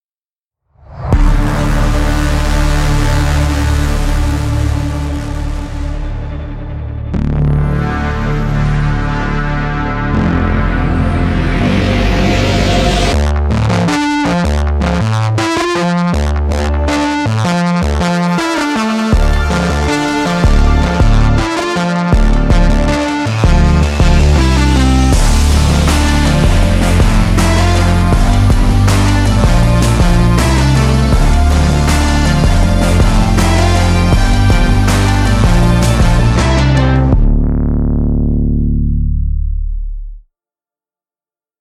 Karanyi Sounds Analog Tales 2 是一款为 NI Kontakt 6 设计的合成器音色库，可以轻松地创建温暖的复古声音、失真的过载贝斯和序列、以及动态的纹理。